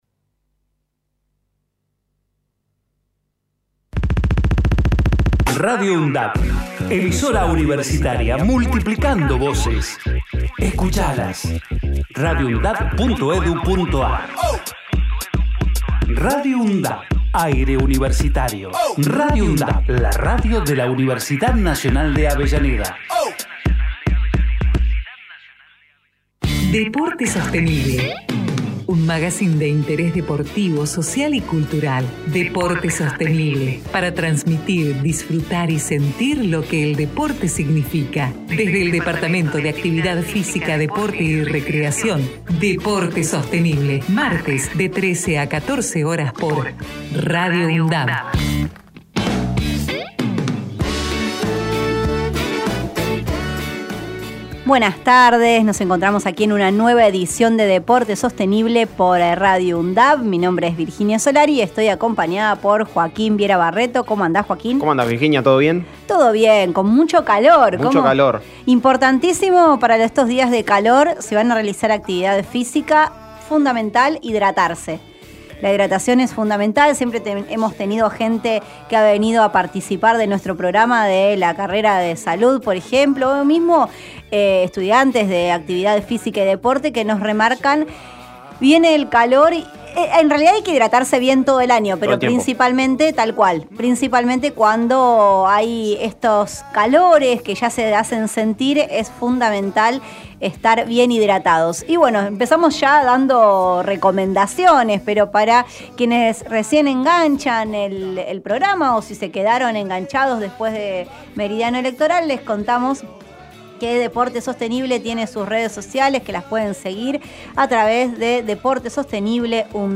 Deporte sostenible Texto de la nota: Deporte Sostenible En cada programa se busca abordar la vinculación estratégica entre gestión deportiva, desarrollo sostenible, salud, cultura, medio ambiente e inclusión social, realizando entrevistas, columnas especiales, investigaciones e intercambio de saberes. Magazine de interés deportivo, social y cultural que se emite desde septiembre de 2012.